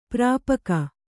♪ prāpaka